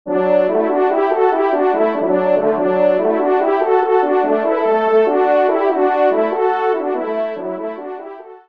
20 sonneries pour Cors et Trompes de chasse